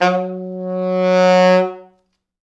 Index of /90_sSampleCDs/East West - Quantum Leap Horns Sax/Quantum Leap Horns Sax/T Sax fts